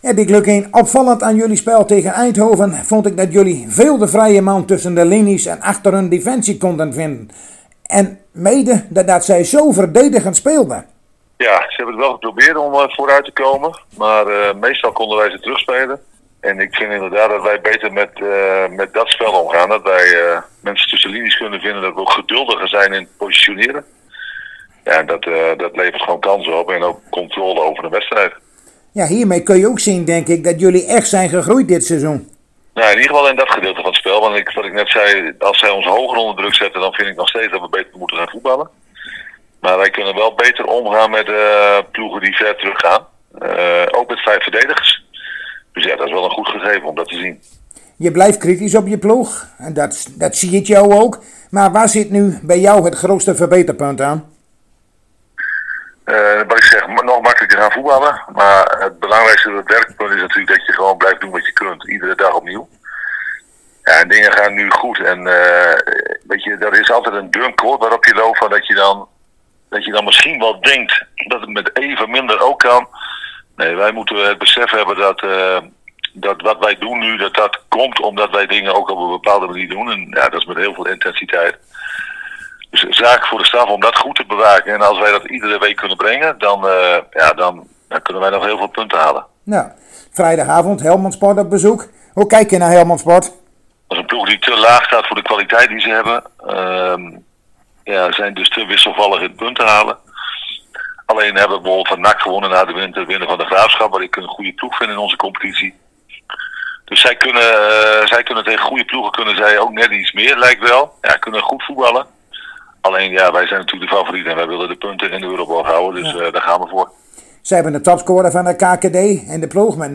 Zojuist spraken wij weer met Dick Lukkien en we keken even terug op de overwinning tegen FC Eindhoven en keken vooruit op de wedstrijd van vrijdagavond tegen Helmond Sport.